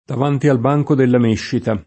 dav#nti al b#jko della m%ššita] (Cicognani)